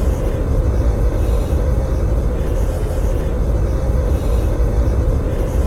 ogg / general / combat / suit / move2.ogg